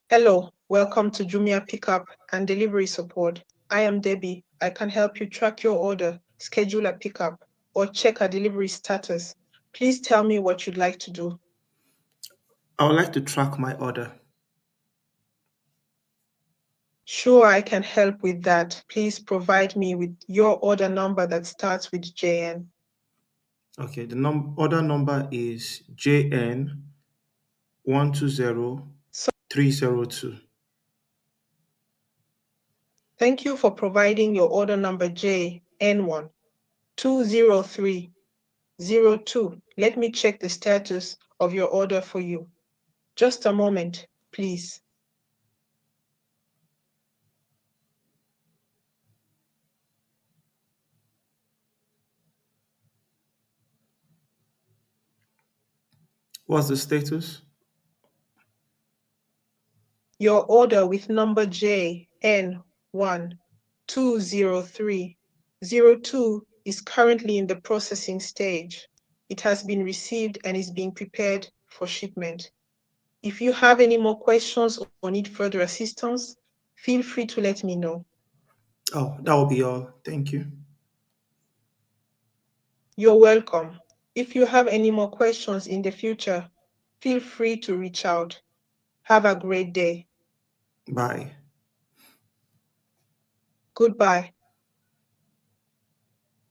subscription-based army of call center voice agents
with 80+ African voices.